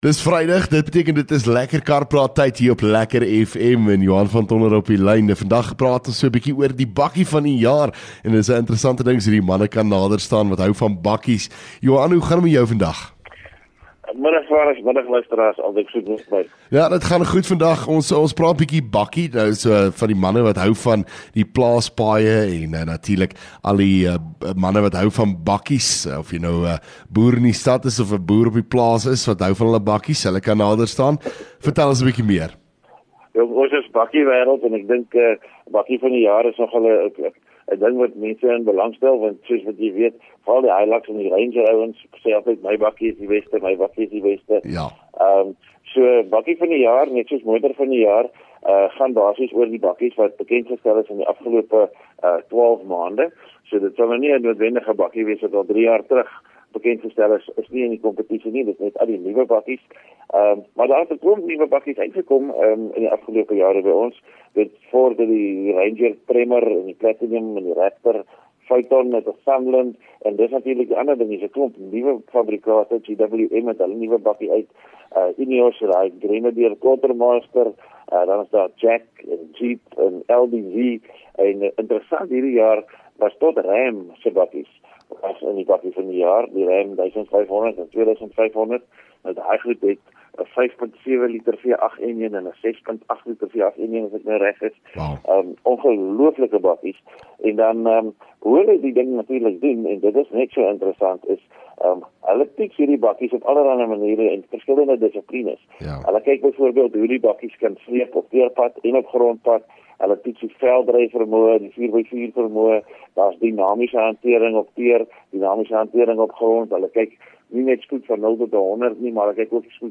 LEKKER FM | Onderhoude 27 Sep Lekker Kar Praat